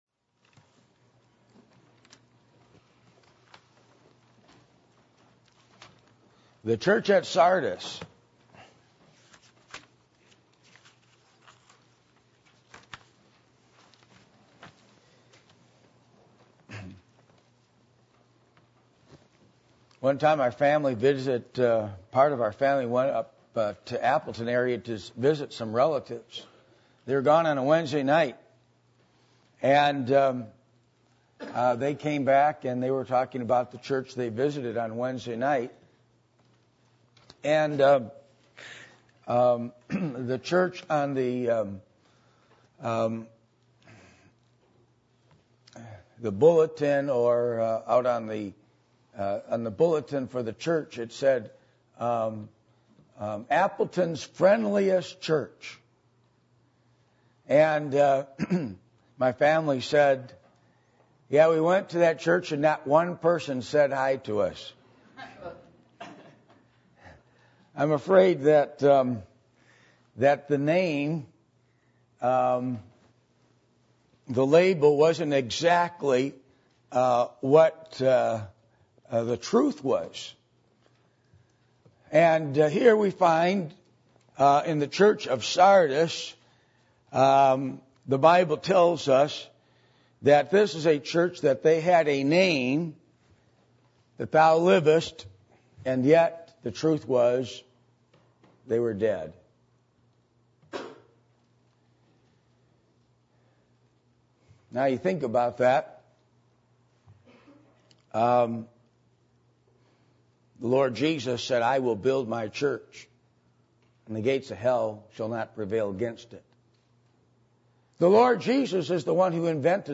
Revelation 3:1-6 Service Type: Sunday Morning %todo_render% « How Do I Grow Through The Study Of Scripture?